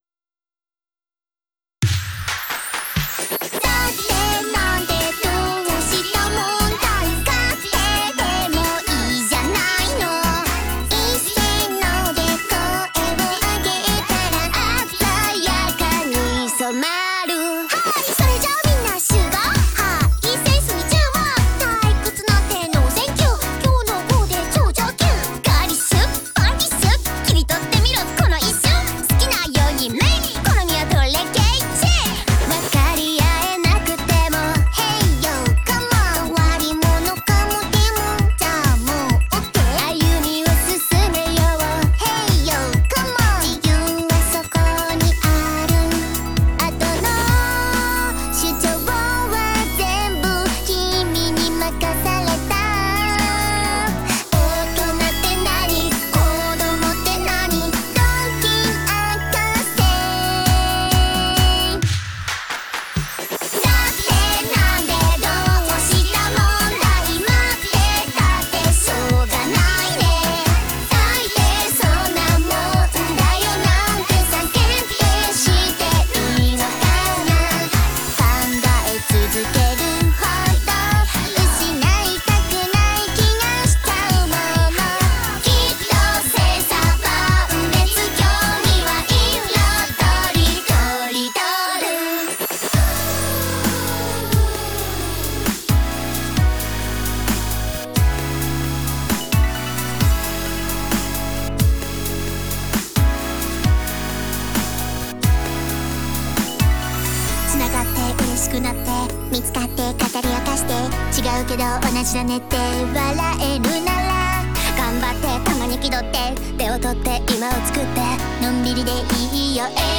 RAP BATTLE YO
if you like denpa or apop its good
Ooooooh, funky.